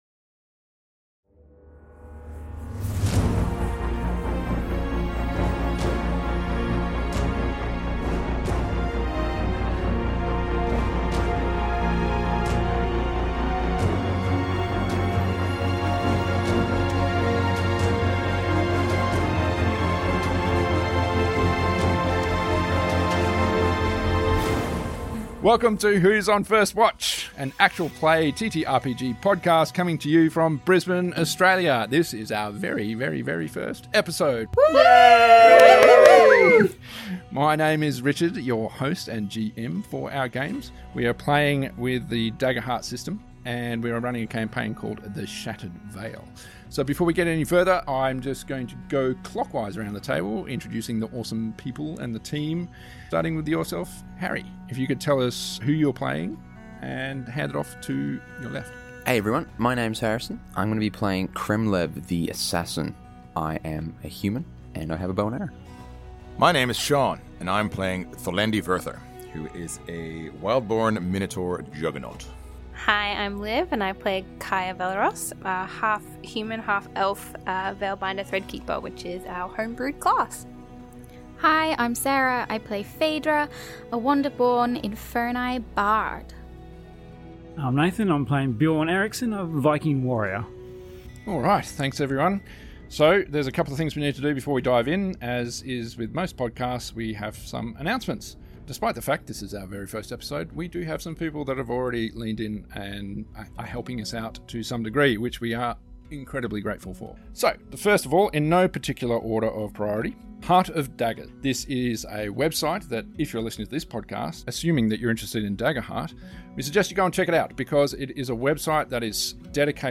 Whosonfirstwatch - A Daggerheart System TTRPG actual play podcast.